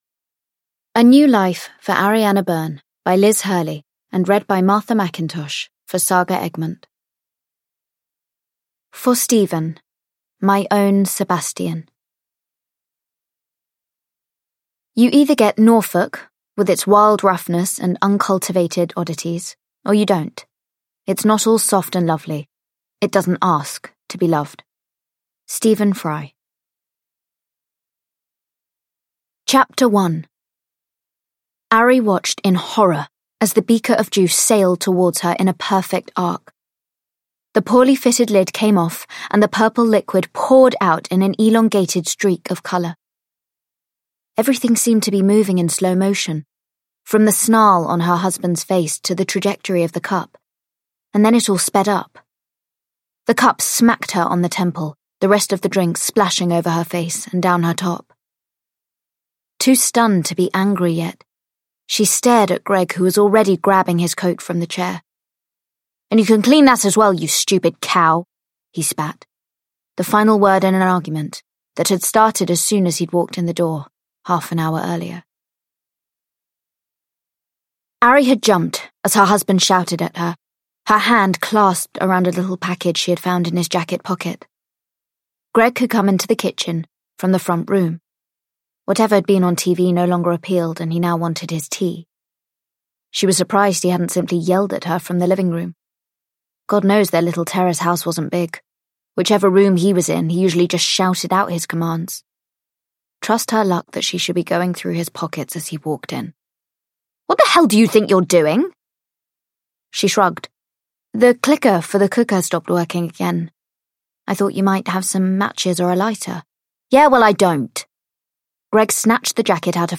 A New Life for Ariana Byrne / Ljudbok